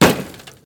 hit.ogg